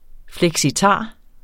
Udtale [ flεgsiˈtɑˀ ]